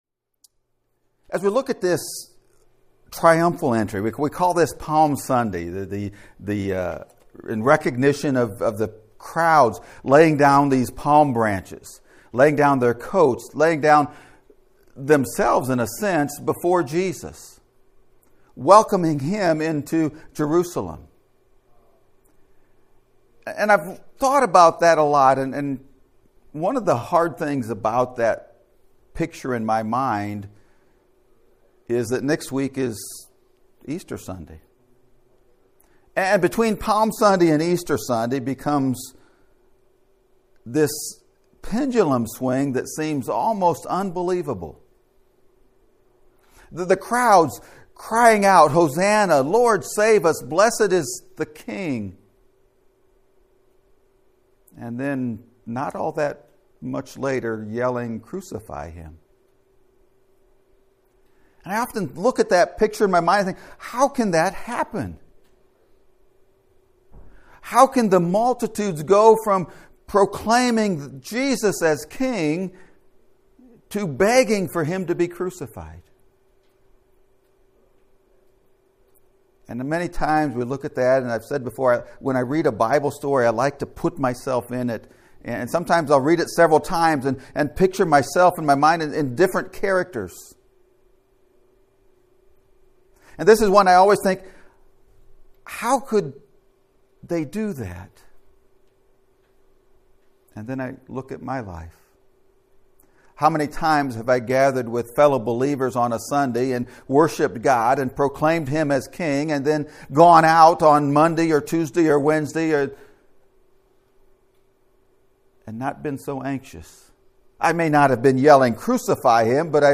Proclaiming the King of KINGS (Sermon Audio)